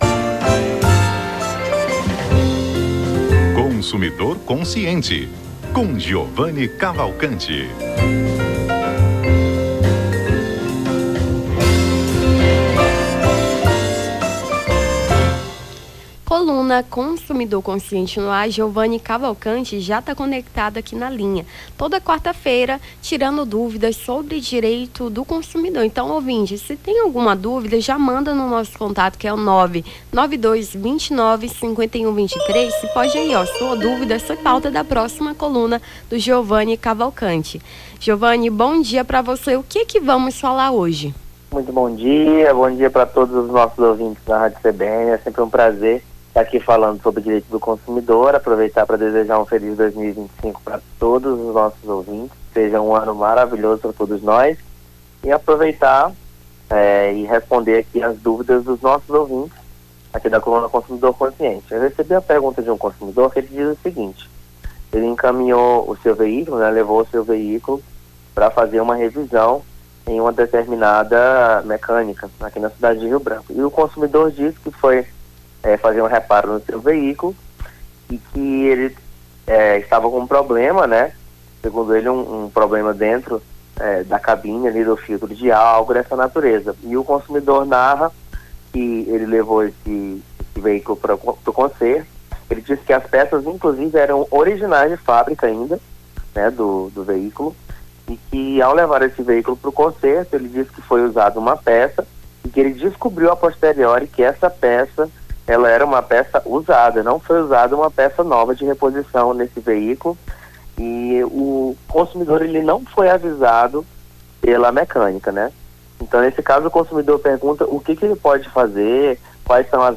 Consumidor Consciente: Advogado responde às dúvidas dos ouvintes sobre diretos do consumidor